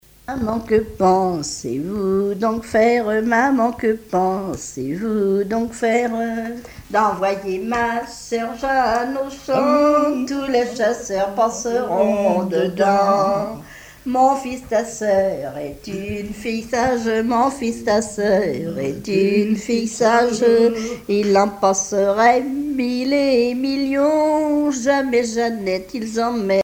Chansons traditionnelles
Pièce musicale éditée